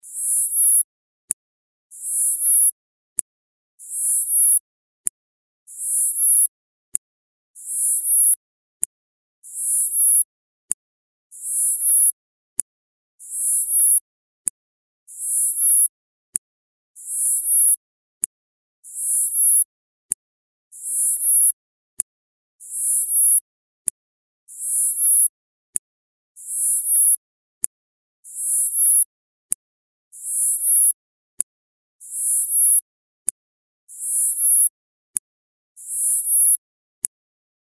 ウマオイの鳴き声